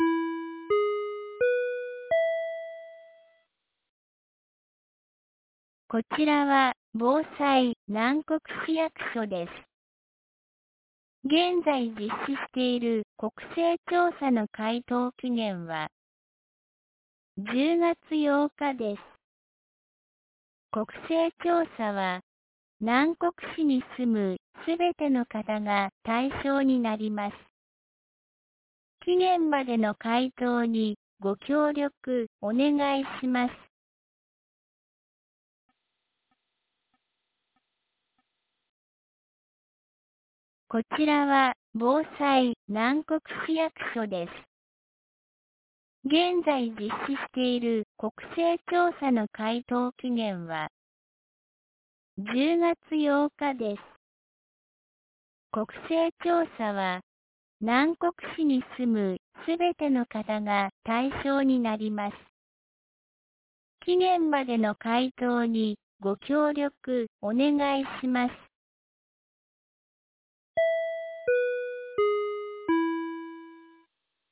2025年10月08日 10時01分に、南国市より放送がありました。